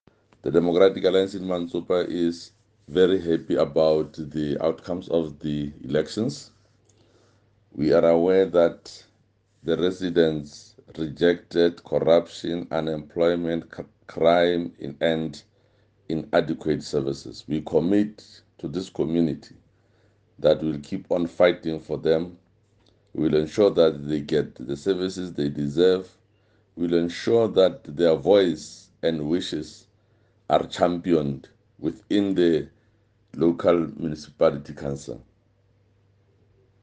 Sesotho soundbites by Jafta Mokoena MPL.